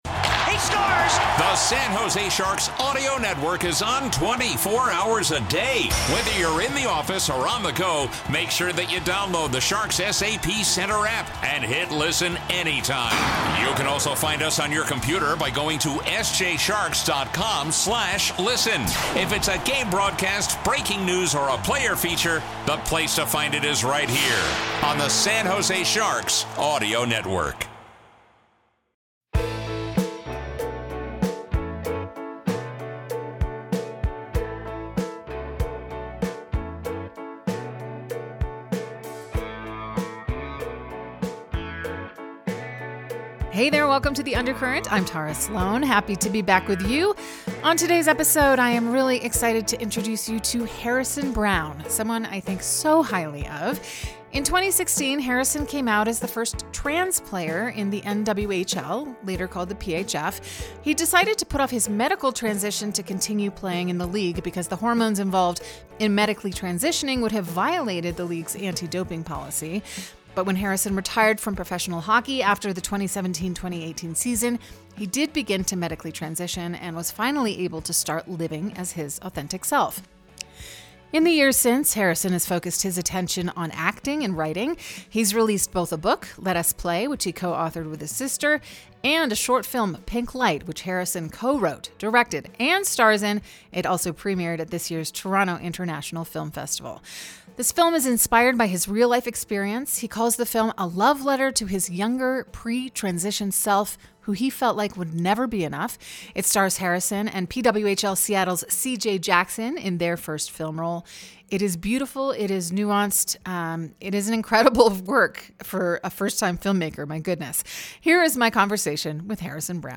Broadcaster, musician, and talk show host Tara Slone talks with unique people around hockey and the San Jose Sharks community.